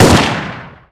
sol_reklam_link sag_reklam_link Warrock Oyun Dosyalar� Ana Sayfa > Sound > Weapons > M4 Dosya Ad� Boyutu Son D�zenleme ..
WR_fire.wav